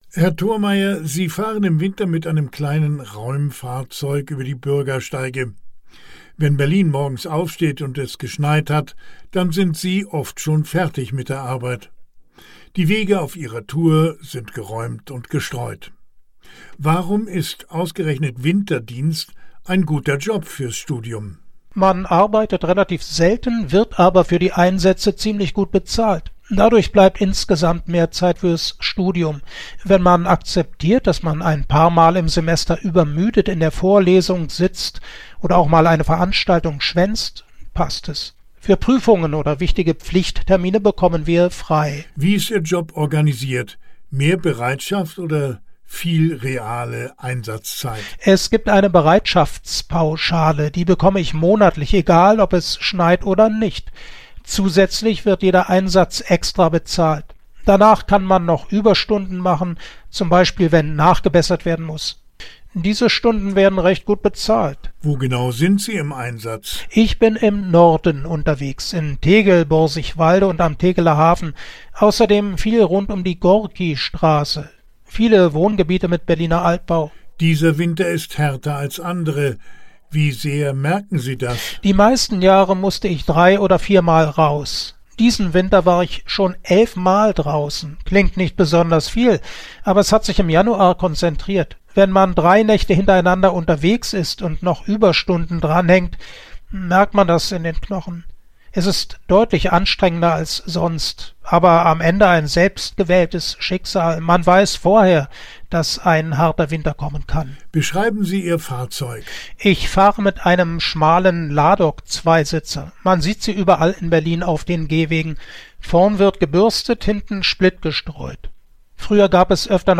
Interview 19.02.2026